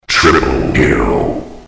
triple-kill-1.mp3